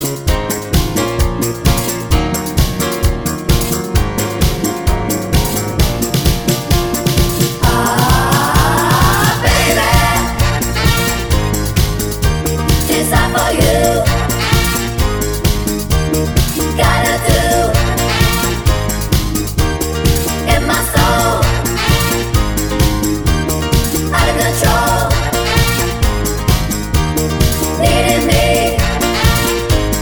One Semitone Down Pop (1980s) 4:33 Buy £1.50